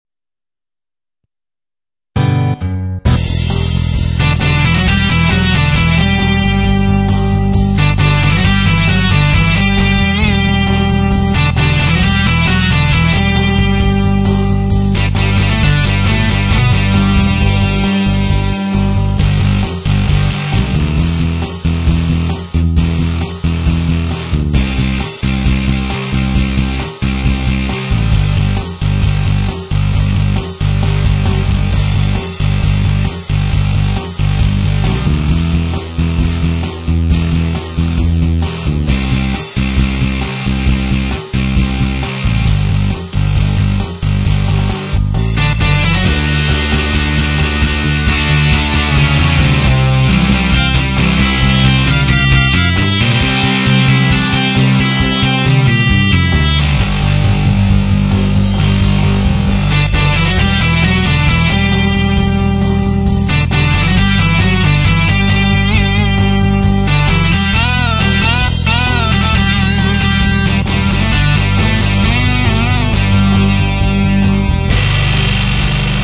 Play along with this beefed up version of Whiskey in the Jar.
SOLO PART
whiskeysolo.mp3